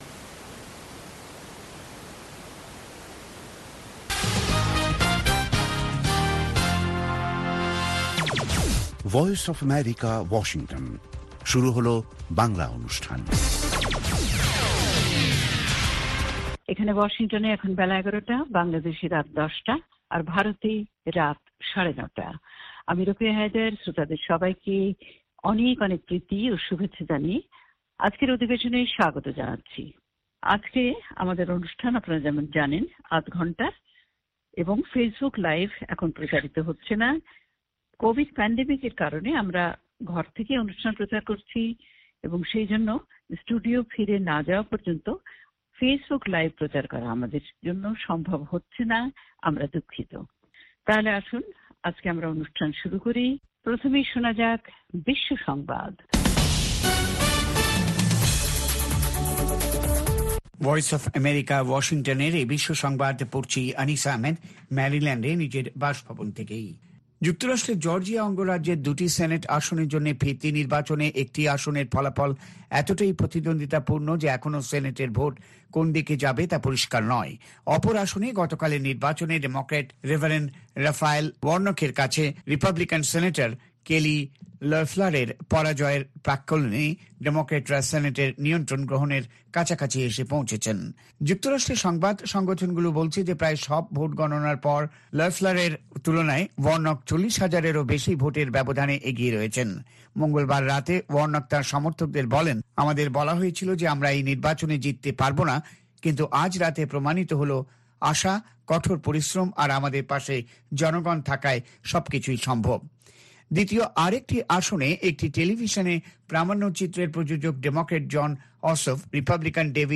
অনুষ্ঠানের শুরুতেই রয়েছে আন্তর্জাতিক খবরসহ আমাদের ঢাকা এবং কলকাতা সংবাদদাতাদের রিপোর্ট সম্বলিত বিশ্ব সংবাদ, বুধবারের বিশেষ আয়োজন হ্যালো ওয়াশিংটন। আর আমাদের অনুষ্ঠানের শেষ পর্বে রয়েছে যথারীতি সংক্ষিপ্ত সংস্করণে বিশ্ব সংবাদ।